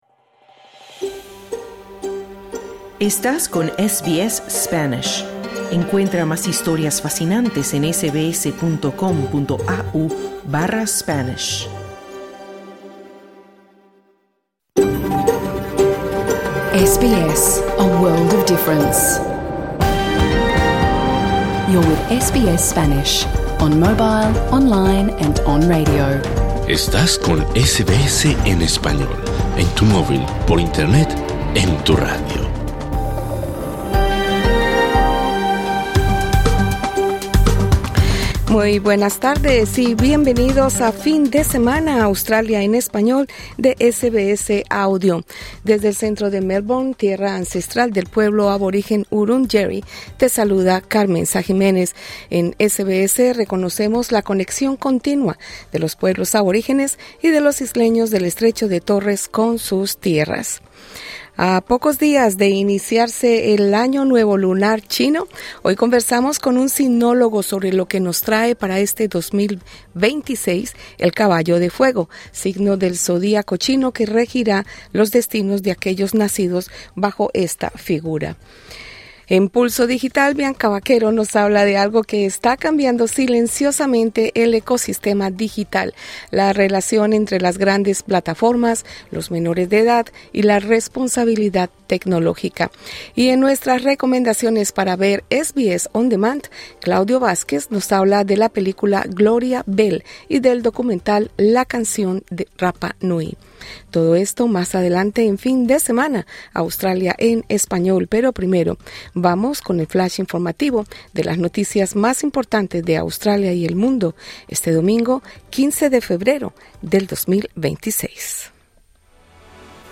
Programa 15/02/26: Conversamos con un sinólogo sobre las características que identifican al Caballo de Fuego, cuando se inicia el Año Lunar en el calendario chino. Además, tenemos el segmento Pulso Digital y las recomendaciones para ver SBS On Demand.
Programa de radio